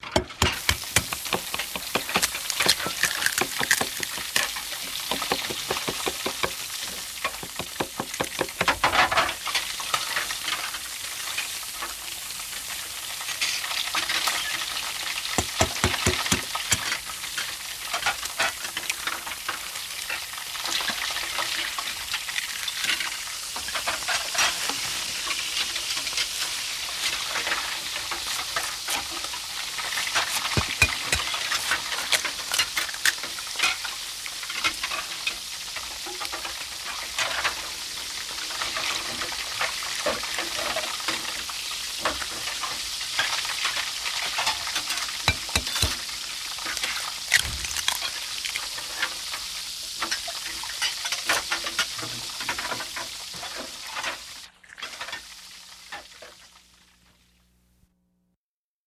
Звуки повара, кухни
Громкие звуки работы на кухне ресторана